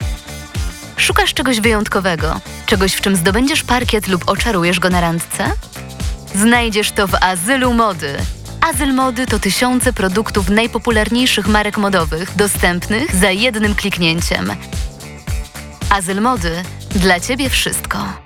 Udzielam głosu do nagrań lektorskich – audiobooki, teksty medytacyjne, reklamy, filmy instruktażowe, dokumentalne i fabularne.